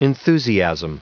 Prononciation du mot enthusiasm en anglais (fichier audio)
Prononciation du mot : enthusiasm